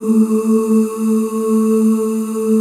A2 FEM OOS.wav